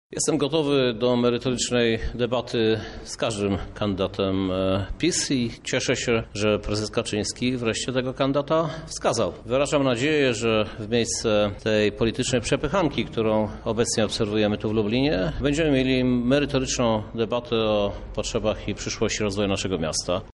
O kandydacie prawa i sprawiedliwości i przyszłych debatach wyborczych mówi prezydent Lublina, Krzysztof Żuk: